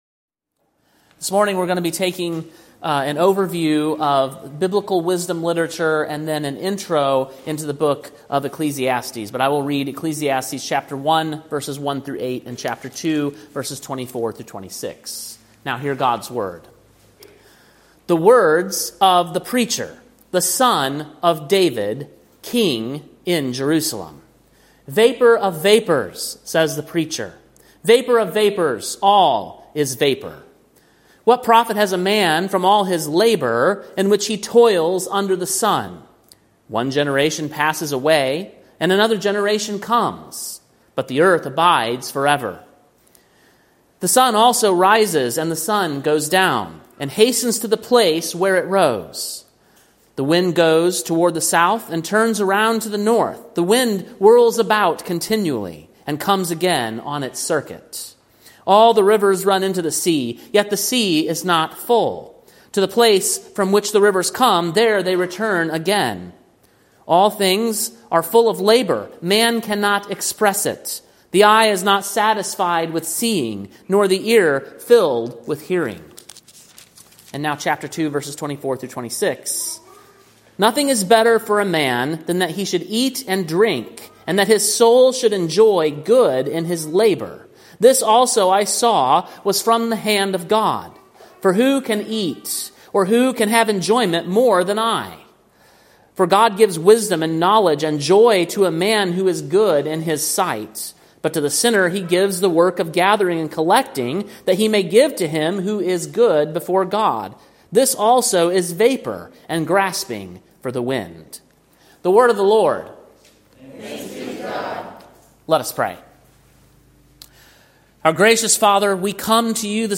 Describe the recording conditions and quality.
Sermon preached on June 22, 2025, at King’s Cross Reformed, Columbia, TN.